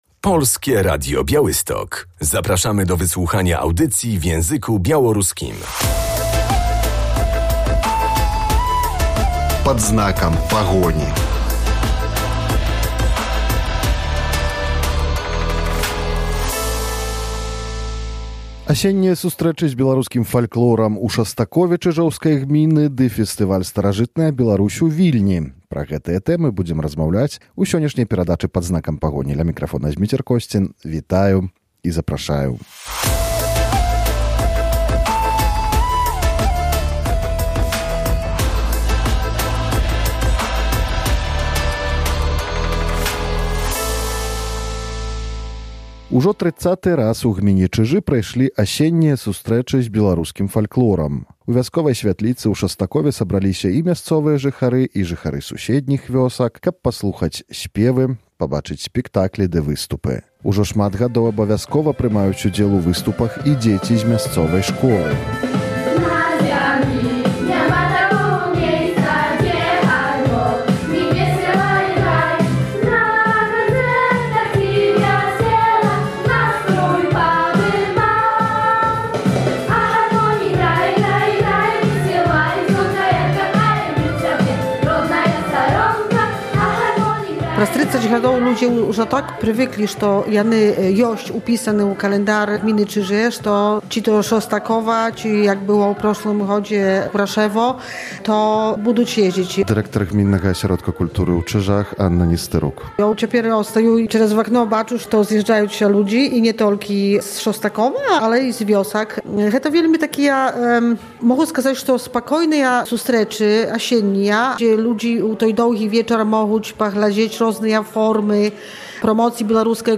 Tradycja, śpiew i spotkania ludzi, którym bliski jest białoruski folklor – to tematy dzisiejszej audycji Pad znakam Pahoni. W gminie Czyże już po raz trzydziesty odbyły się Jesienne Spotkania z Białoruskim Folklorem.